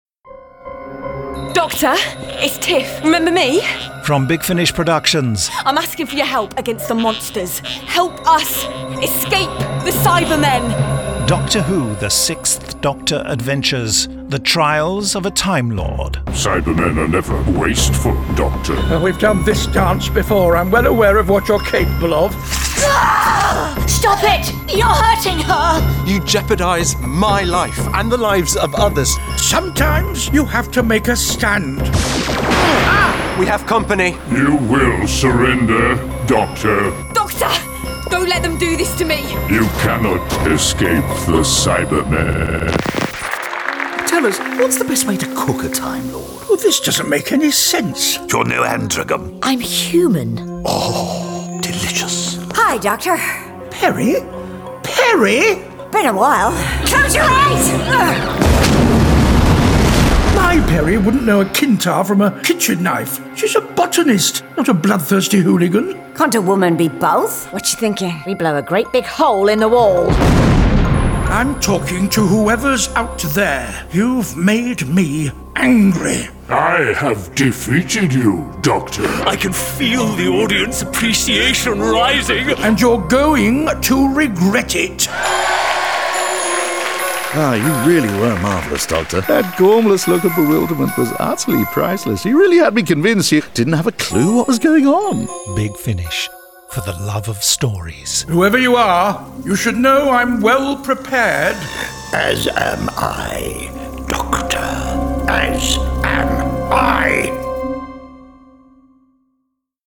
Starring Colin Baker Nicola Bryant